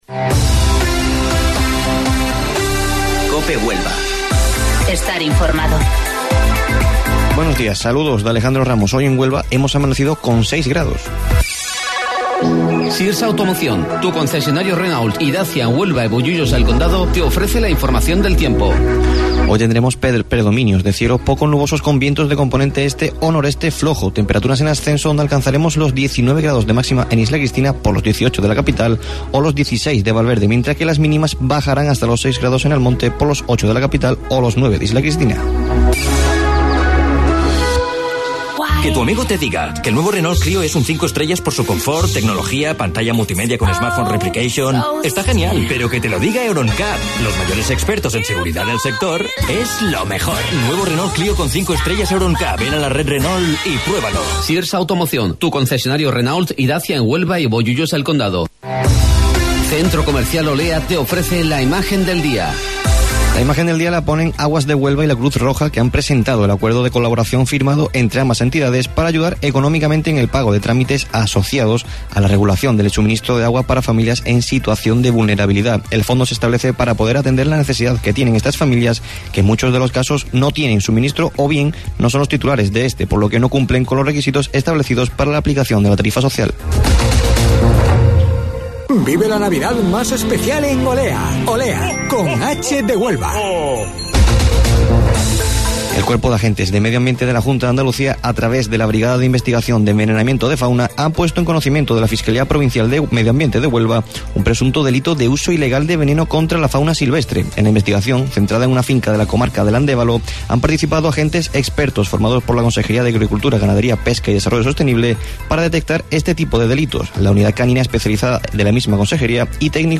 AUDIO: Informativo Local 08:25 del 5 Diciembre